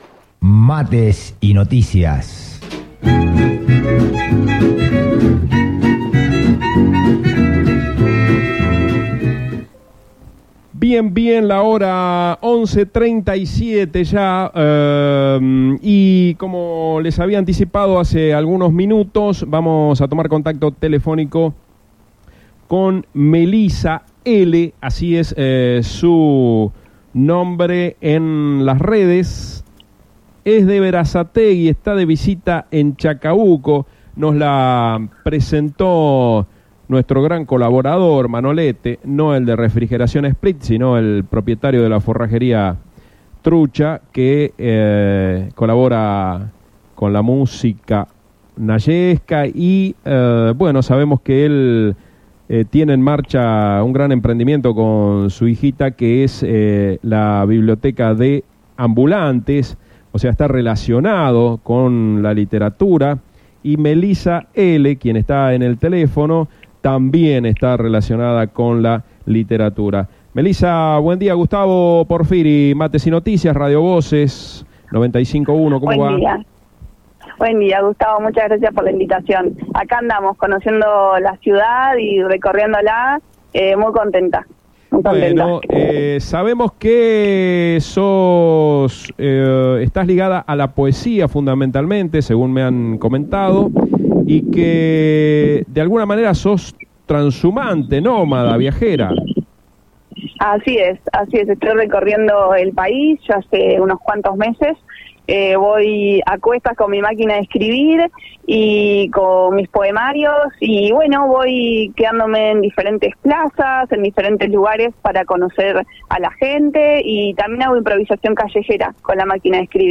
En esta nota realizada en Mates y Noticias (FM Voces 95.1) nos cuenta cómo es ir de pueblo en pueblo haciendo poesía.